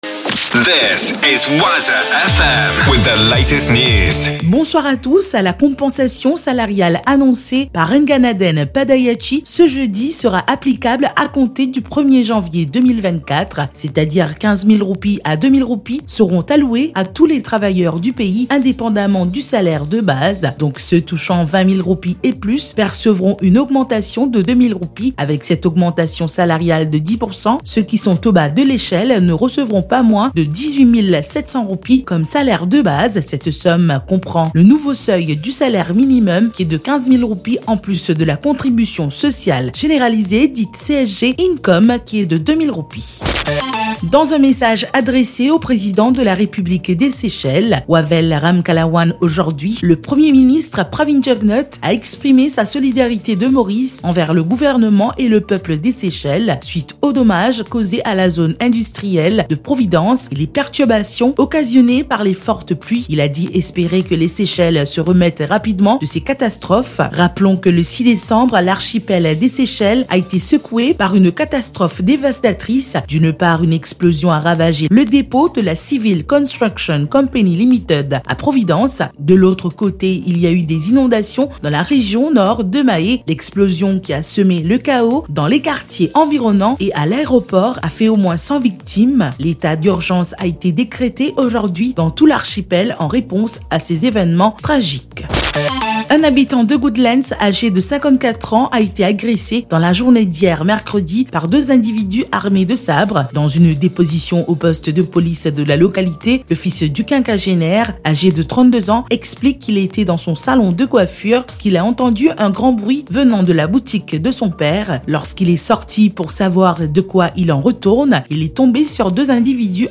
NEWS 17H - 7.12.23